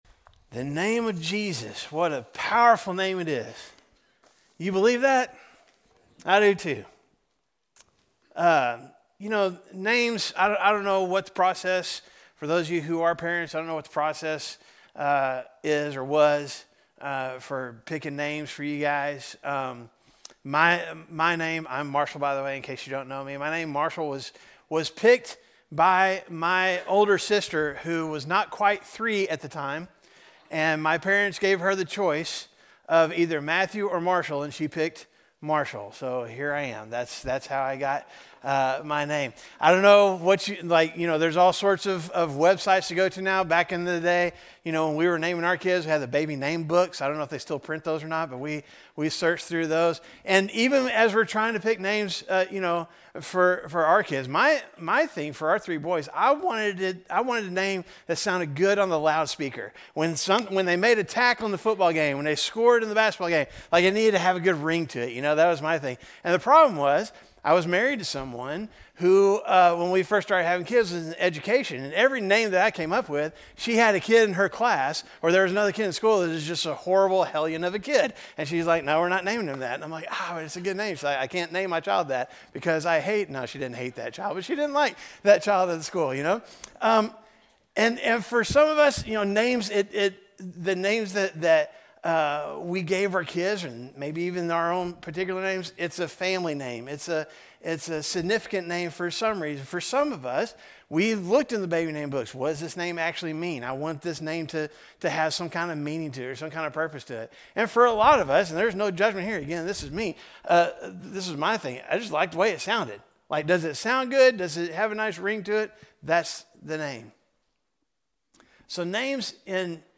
A message from the series "Christmas Playlist."